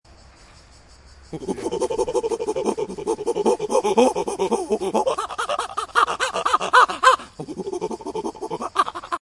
Download Chimpanzee sound effect for free.
Chimpanzee